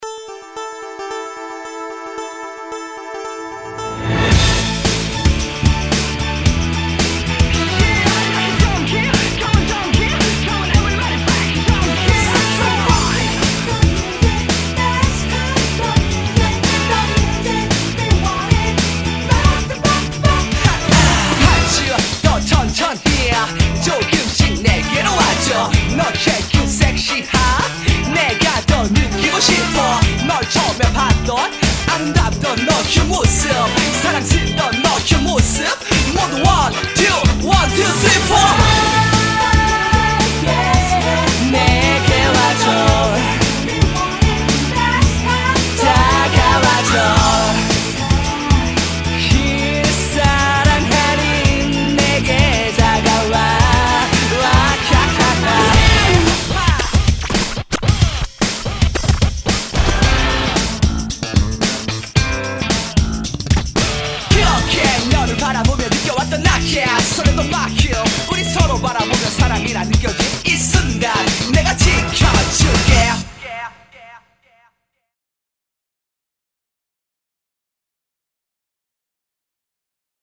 BPM112--1
Audio QualityPerfect (High Quality)